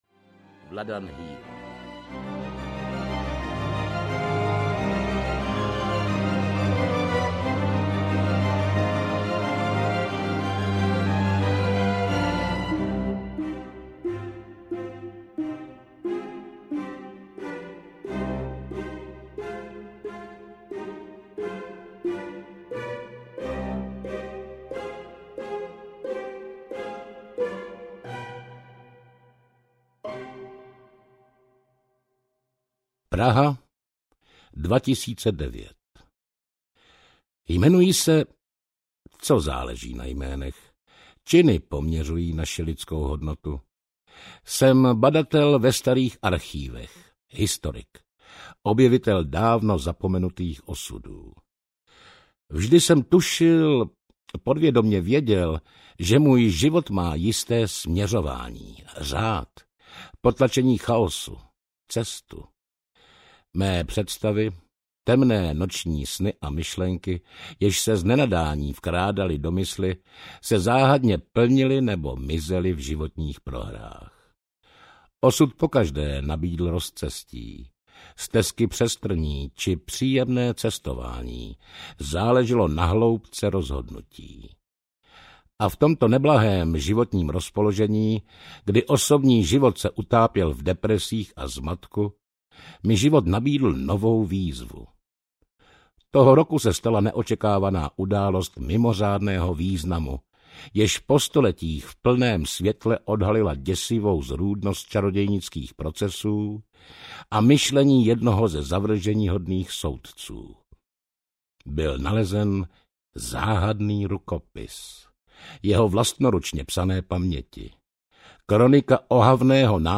Zpověď inkvizitora audiokniha
Ukázka z knihy